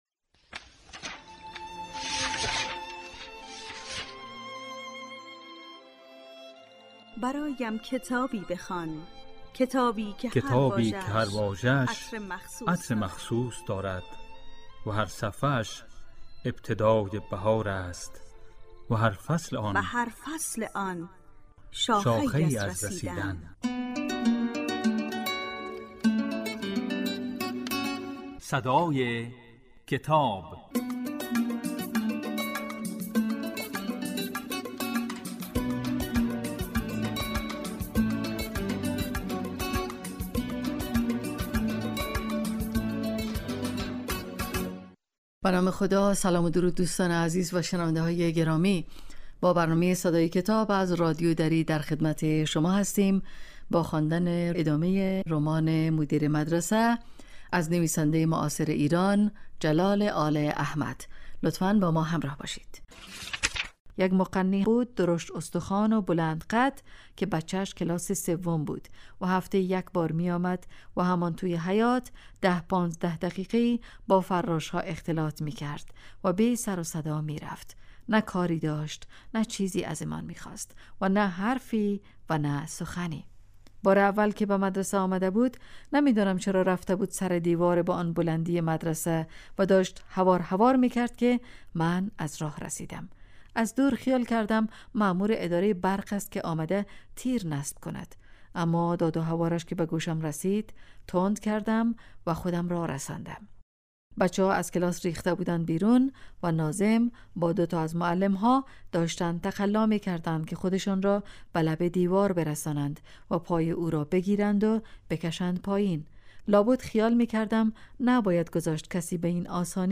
در این برنامه، به دنیای کتاب‌ها گام می‌گذاریم و آثار ارزشمند را می‌خوانیم.